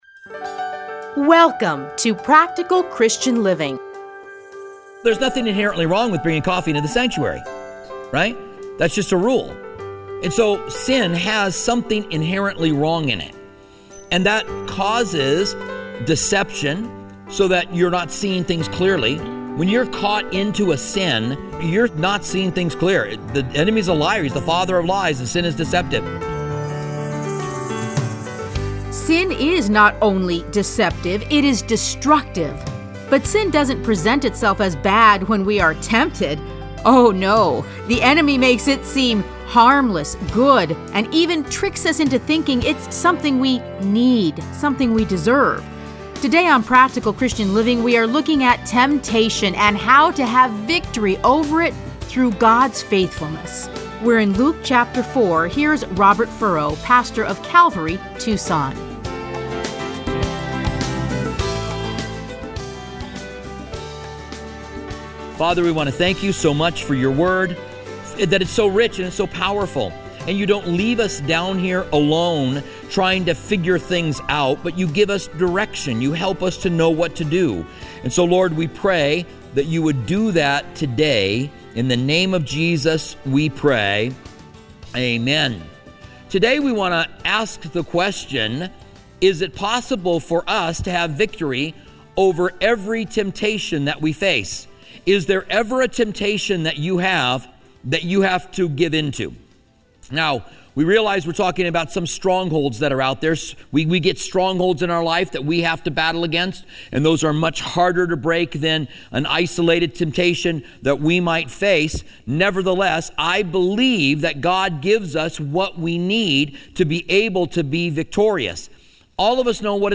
Listen to a teaching from Luke 4:1-15.